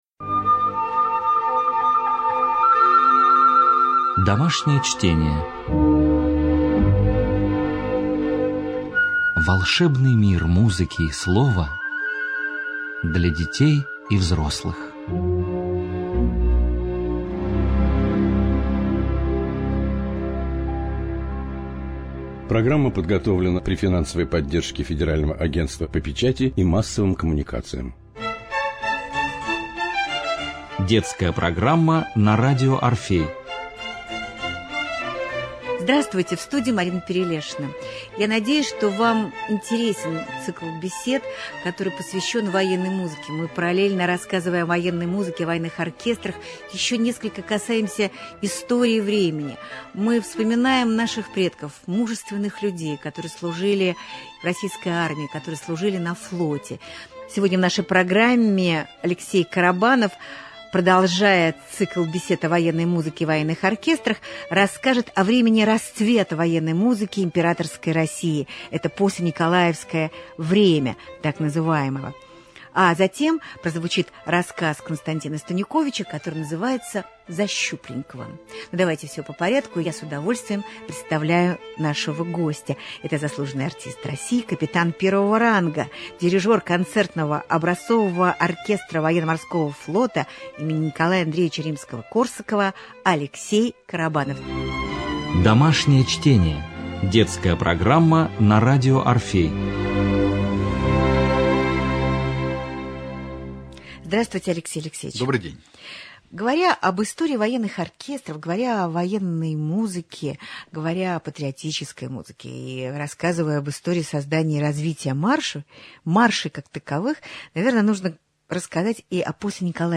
Цикл бесед о патриотической и военной музыке.